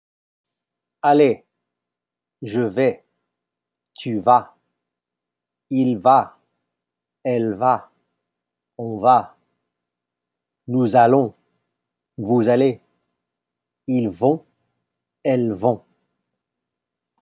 Pronunciation hints: je vais, tu vas, nouszallons, vouszallez, ils/elles vont